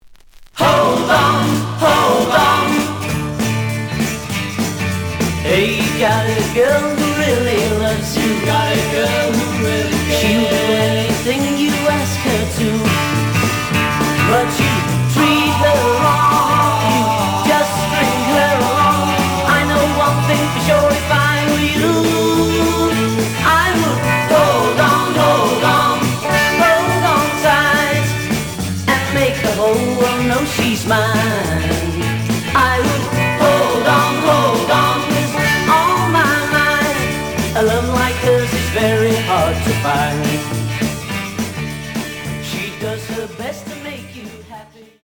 The audio sample is recorded from the actual item.
●Genre: Rock / Pop